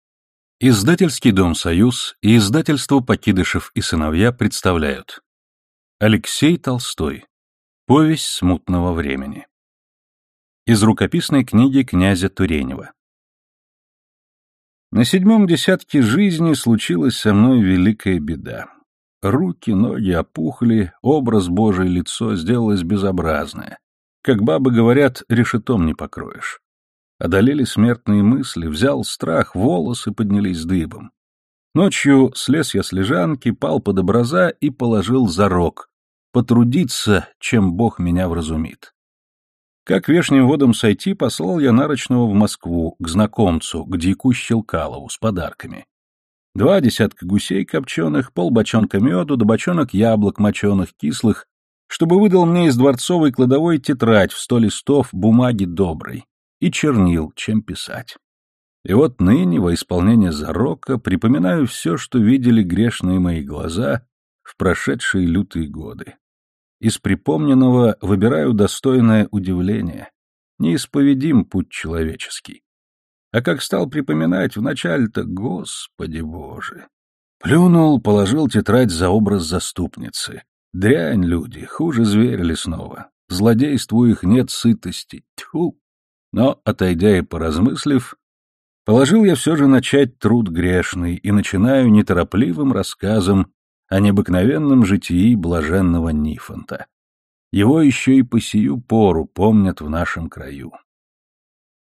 Аудиокнига Повесть Смутного времени | Библиотека аудиокниг